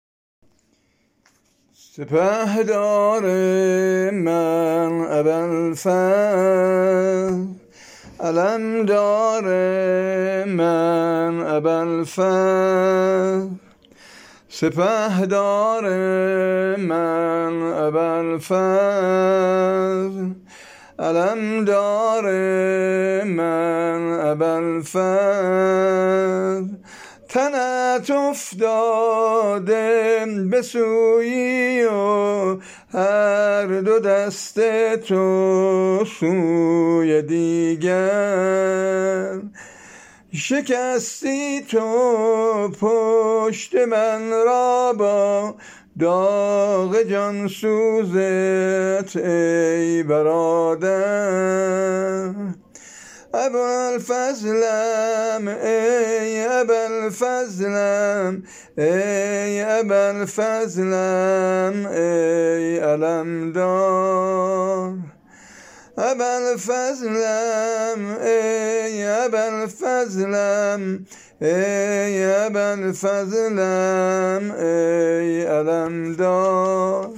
نوحه شهادت حصرت ابوالفضل